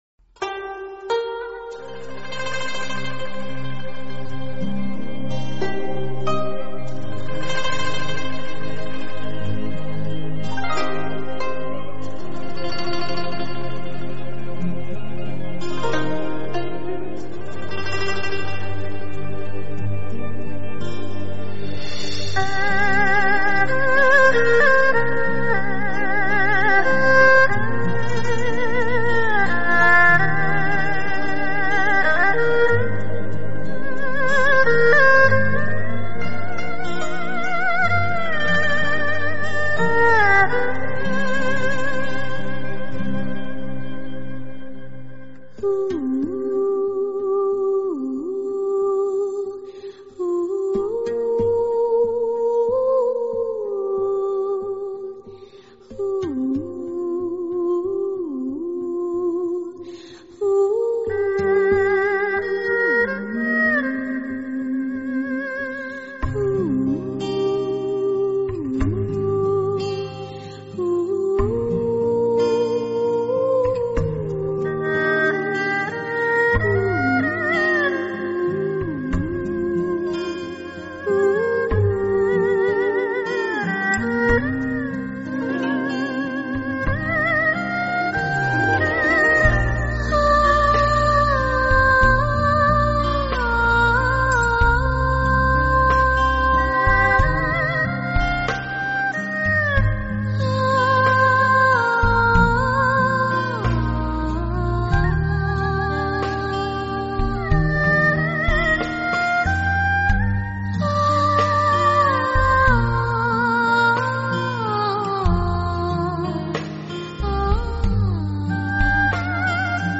佛音 冥想 佛教音乐